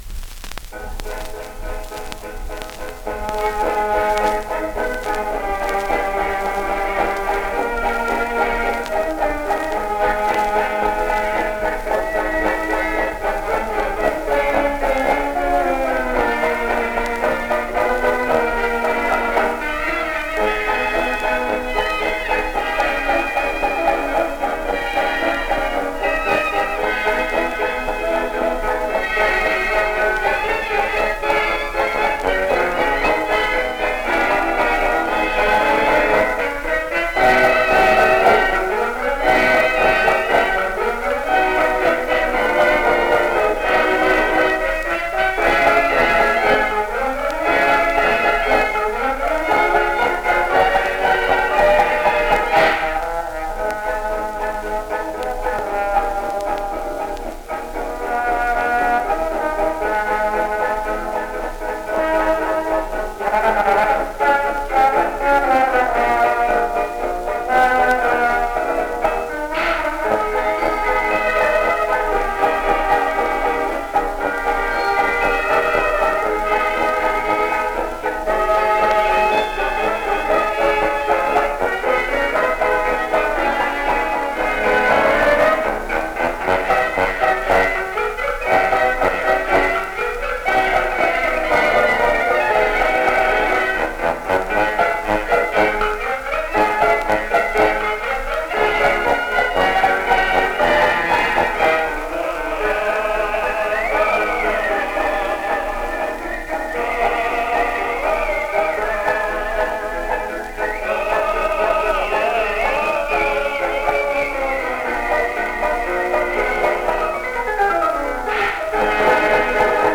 фокстрот